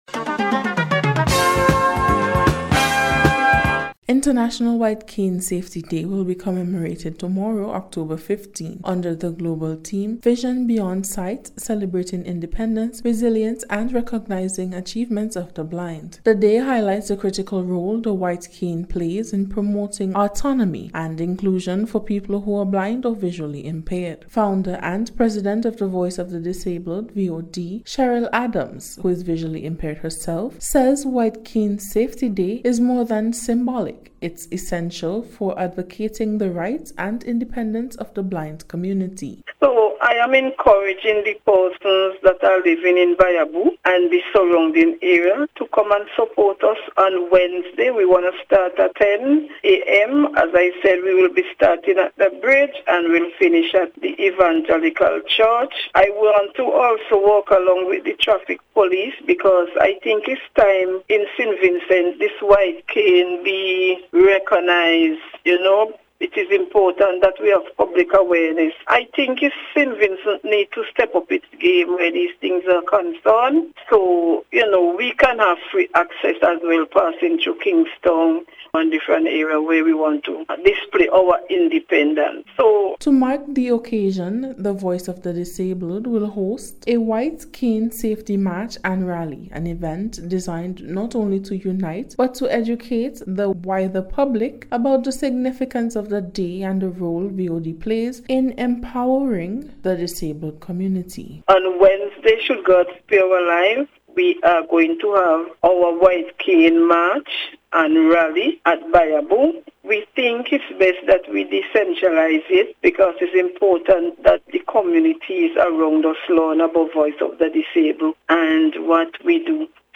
NBC’s Special Report- Tuesday 14th October,2025
WHITE-CANE-DAY-2025-REPORT.mp3